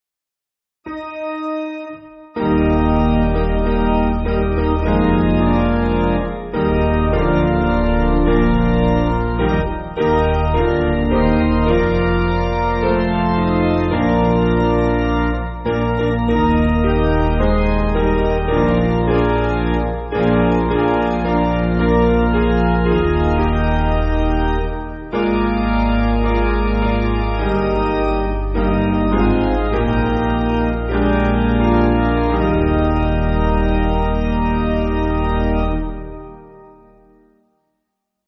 Basic Piano & Organ
(CM)   1/Eb